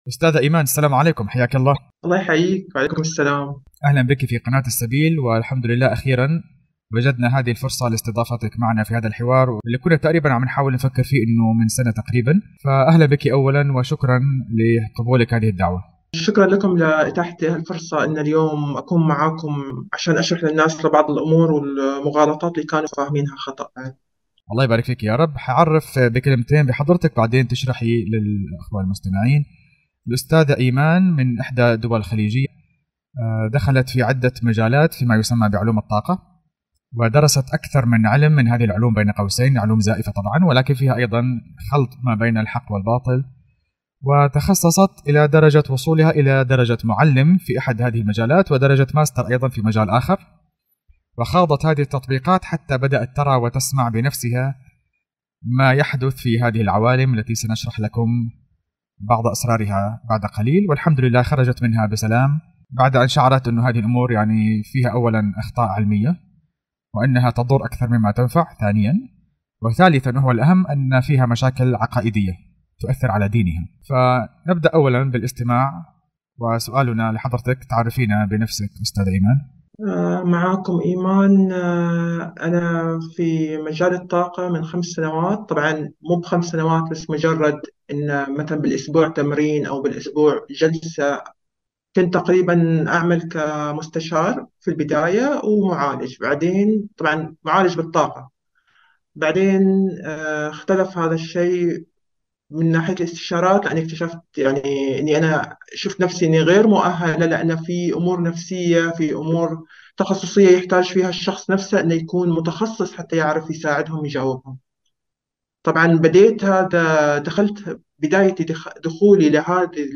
علاج الطاقة والشياطين | حوار مع ماستر سابقة في ثيتا هيلينغ وأكسس بارز والخيمياء • السبيل